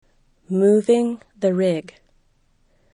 moving (the rig)  muvIŋ